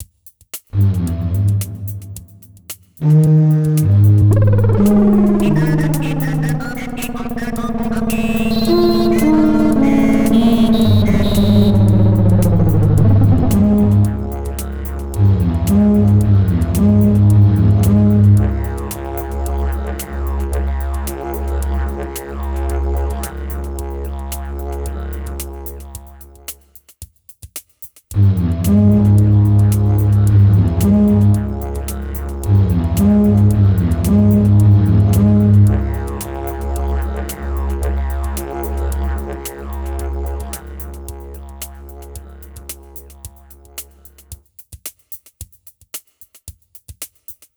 Tecno étnico 2 (bucle)
tecno
melodía
repetitivo
ritmo
sintetizador
Sonidos: Música